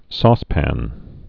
(sôspăn)